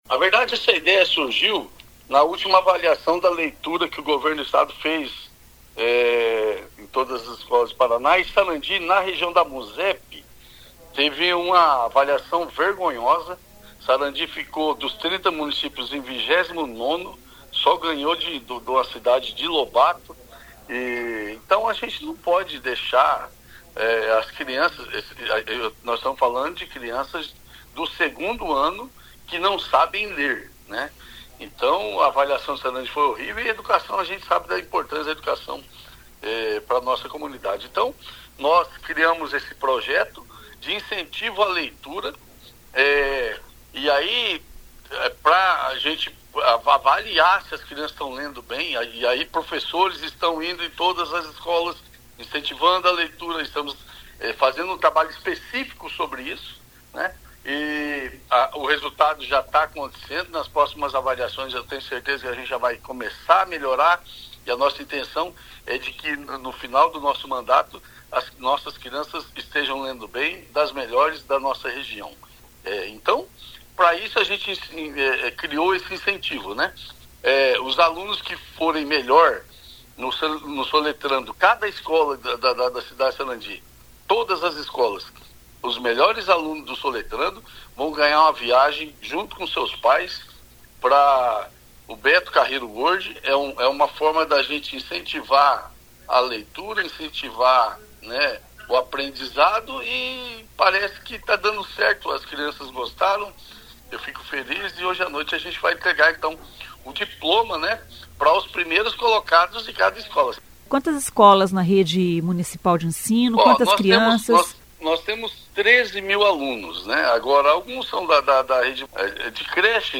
Ouça o que diz o prefeito de Sarandi carlos Alberto de Paula: